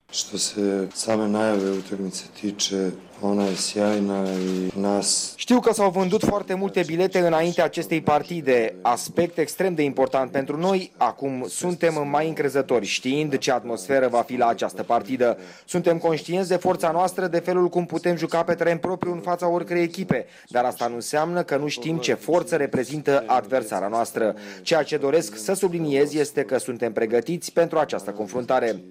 a declarat astăzi la conferința de presă dinaintea returului cu Steaua, din preliminariile Champions League